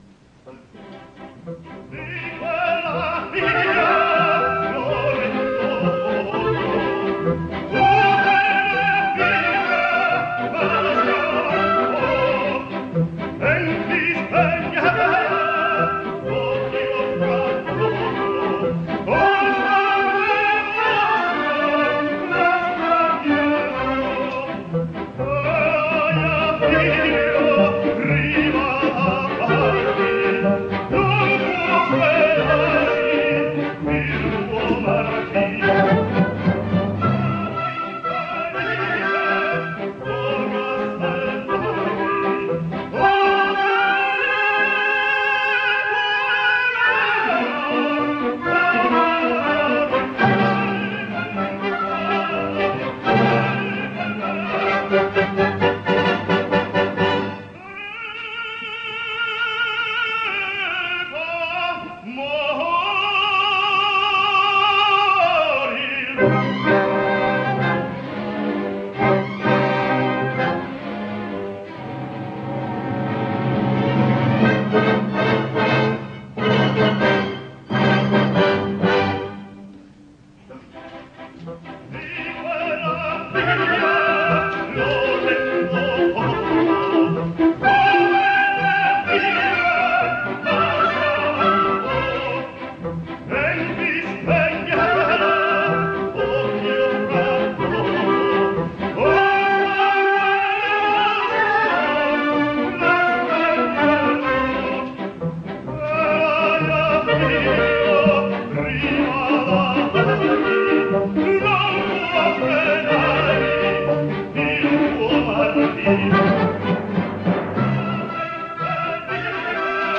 Tenors singing Di quella pira